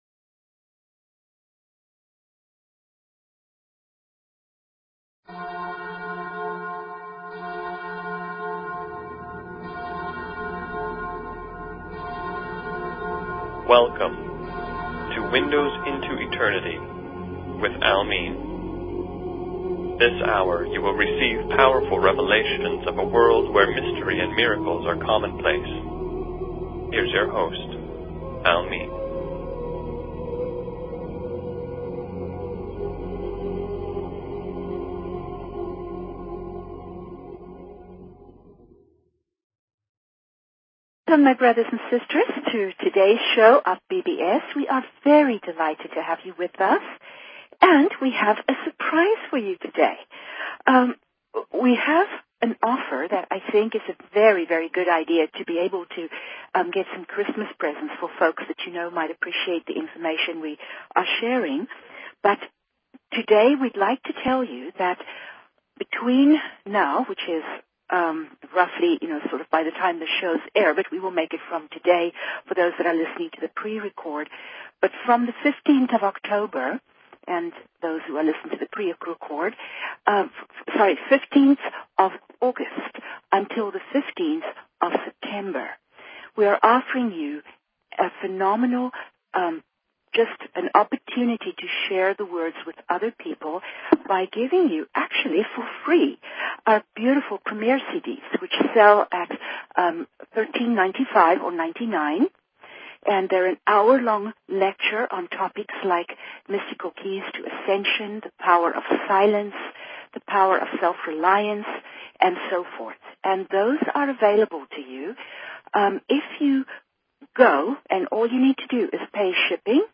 Talk Show Episode, Audio Podcast, Windows_Into_Eternity and Courtesy of BBS Radio on , show guests , about , categorized as